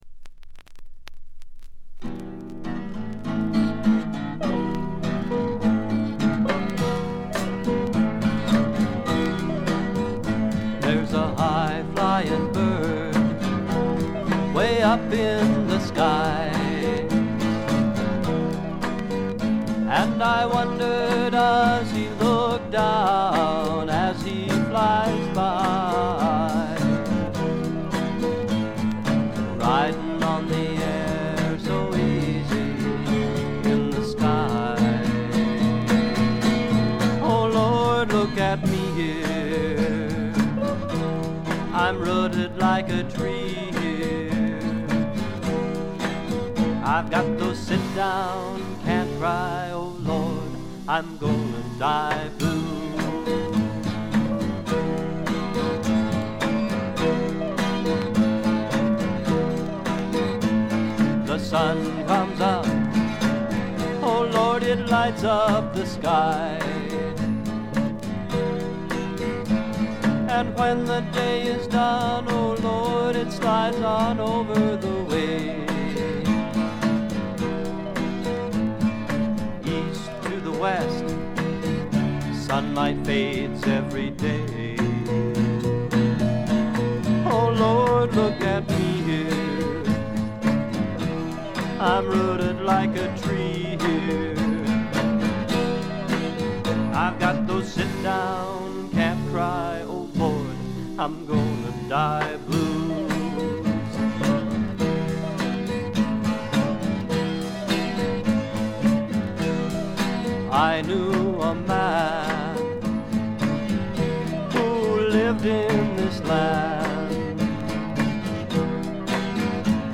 軽微なバックグラウンドノイズやチリプチ少々、プツ音2回ほど。
試聴曲は現品からの取り込み音源です。
Vocals, Twelve-String Guitar, Kazoo
Lead Guitar
Bass Guitar
Percussion
Recorded At - United Theological Seminary